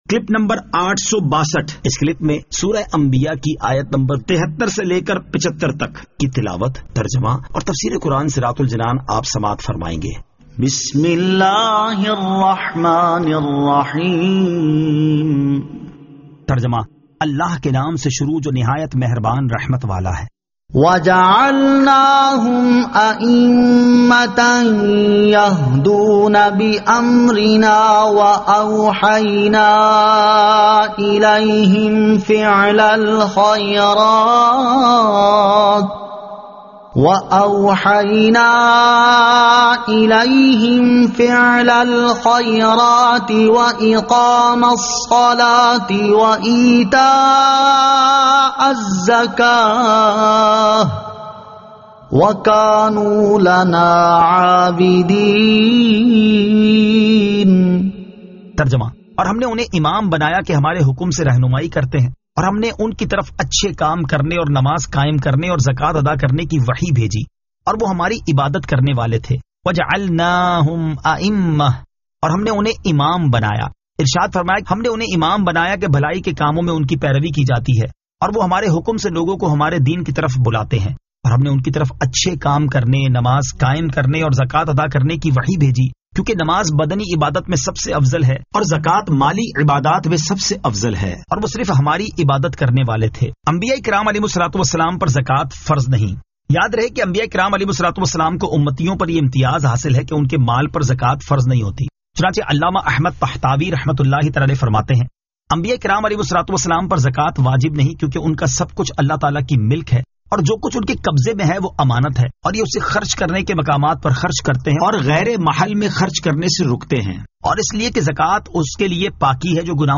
Surah Al-Anbiya 73 To 75 Tilawat , Tarjama , Tafseer
2022 MP3 MP4 MP4 Share سُورَۃُ الأَ نبِیَاٰءِ آیت 73 تا 75 تلاوت ، ترجمہ ، تفسیر ۔